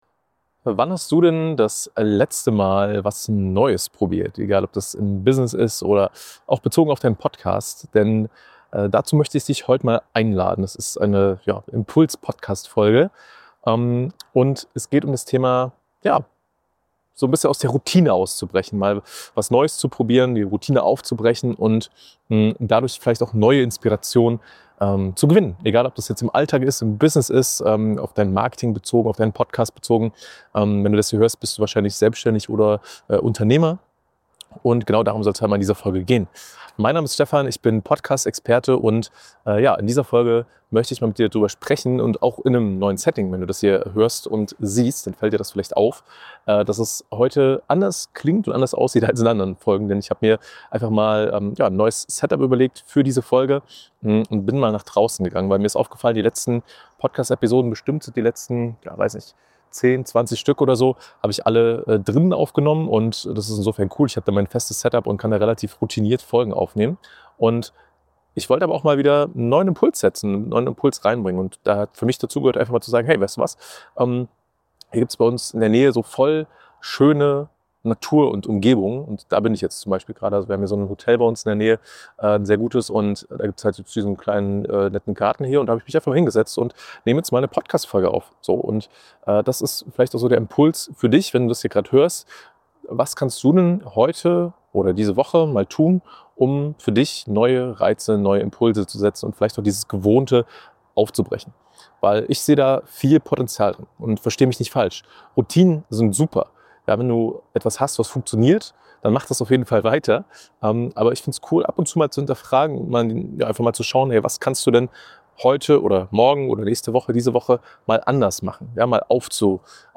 nehme ich dich mit raus aus dem klassischen Studio-Setting – direkt